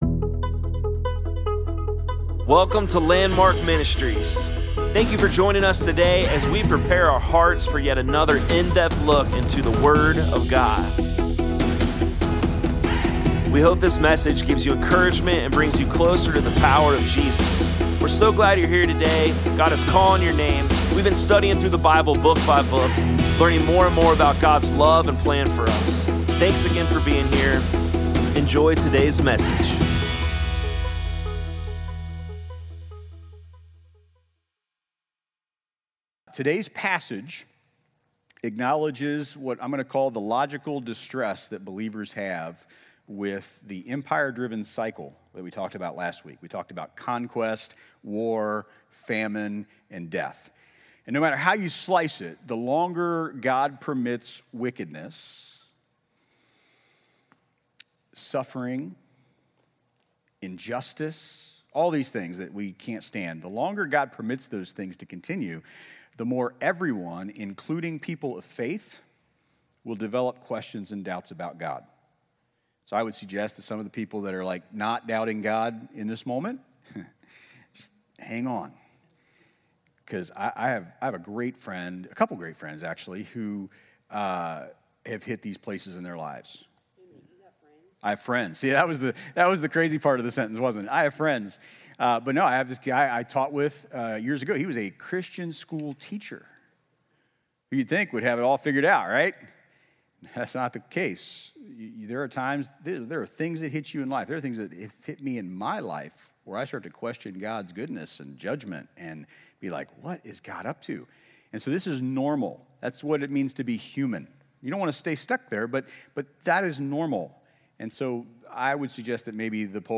Sermons | Landmark Church of Clermont County, Ohio